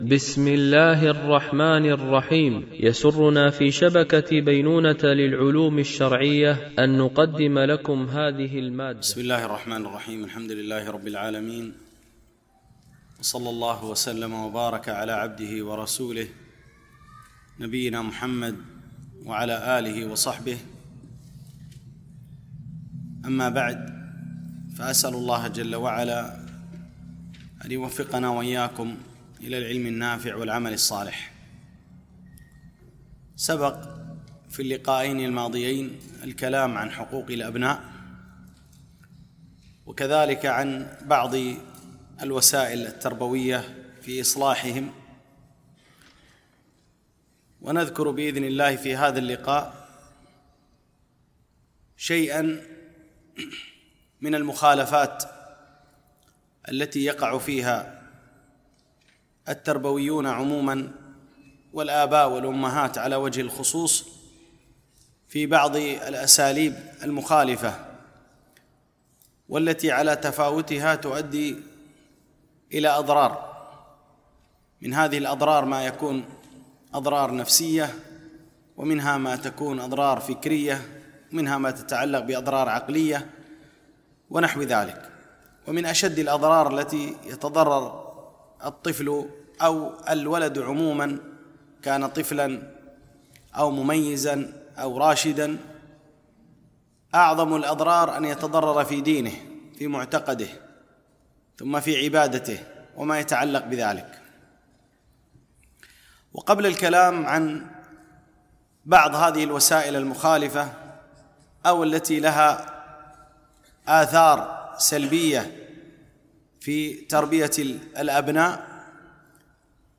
دورة علمية
بمسجد عائشة أم المؤمنين - دبي (القوز 4)